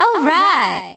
Voice clip
MK7_Rosalina_Alright.oga.mp3